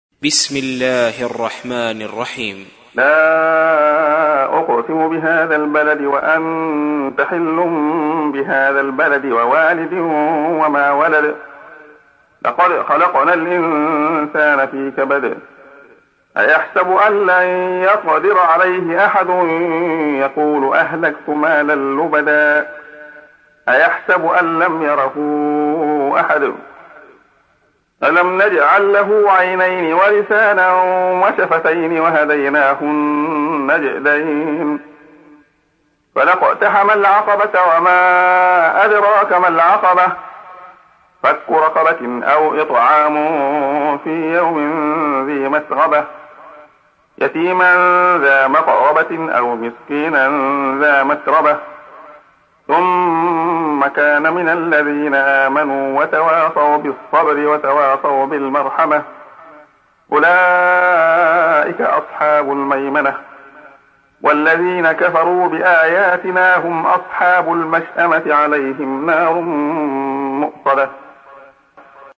تحميل : 90. سورة البلد / القارئ عبد الله خياط / القرآن الكريم / موقع يا حسين